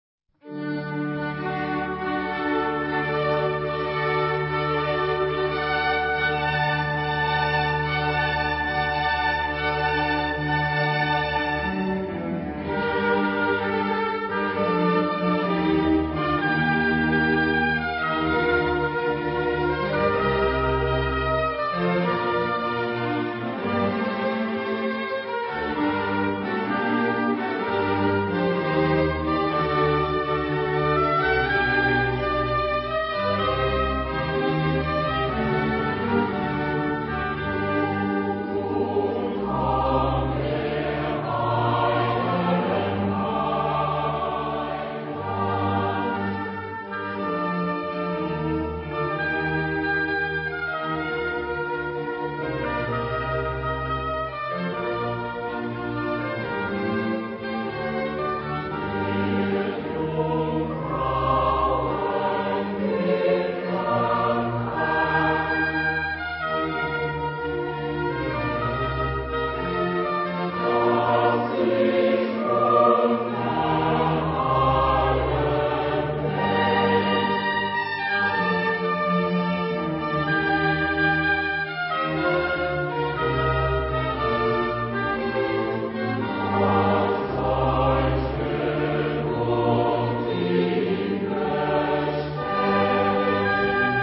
Chor und rezitativ - Arie - Rezitativ - Chor
Genre-Style-Form: Sacred ; Cantata
Type of Choir: SATB  (4 mixed voices )
Soloist(s): Soprano (1) / Alto (1) / Tenor (1) / Bass (1)  (4 soloist(s))
Instrumentation: Baroque orchestra  (8 instrumental part(s))
Instruments: Oboe (2) ; Violin (2) ; Viola (1) ; Cello (1) ; Bass (1) ; Organ (1)
Tonality: D major ; G minor